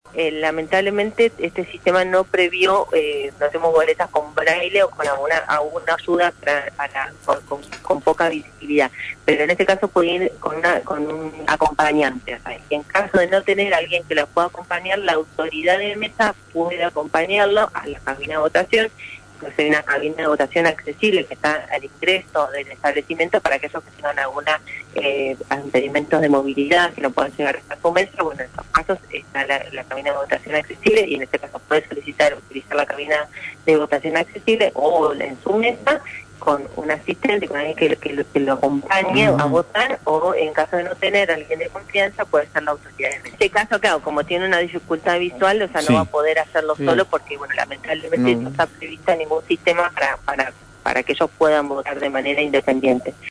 Declaraciones de la Dra. Gutierrez en “Hora de Arrancar”